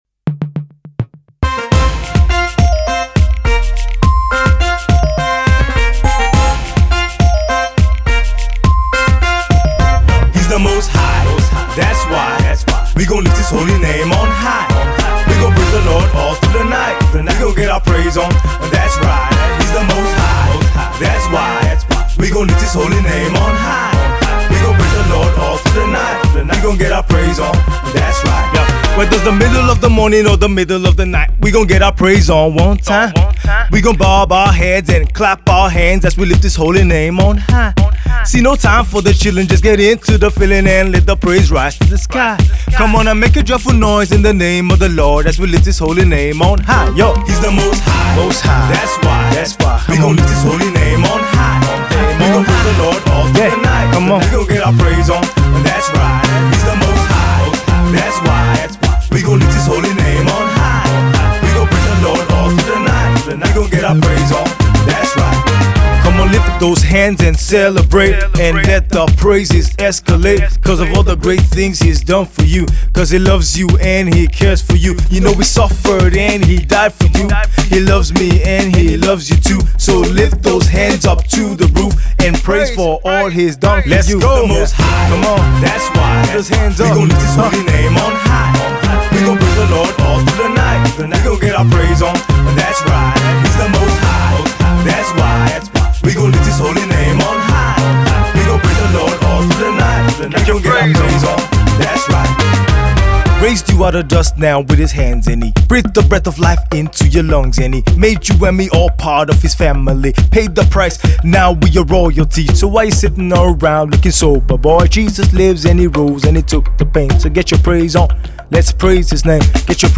Nigerian rapper and songwriter
(Hip Hop Mix)